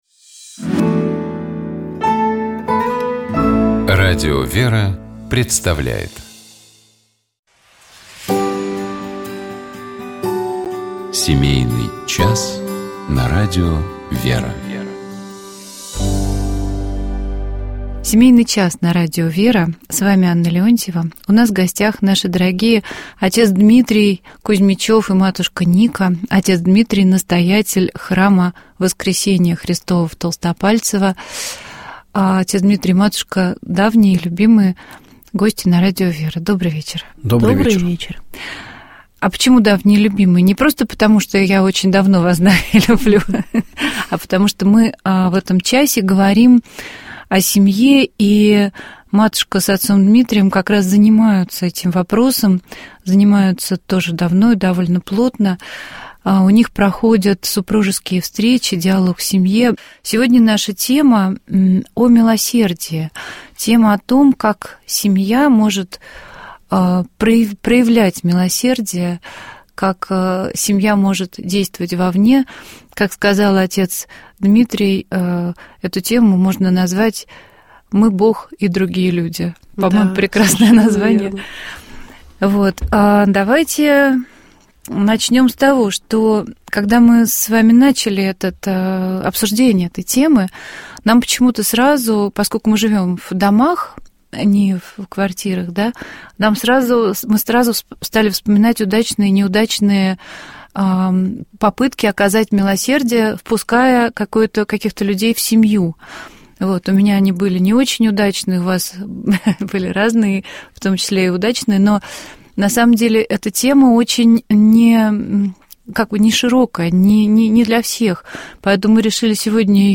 Гость программы — Владимир Легойда, председатель Синодального отдела по взаимоотношениям Церкви с обществом и СМИ, член Общественной палаты РФ.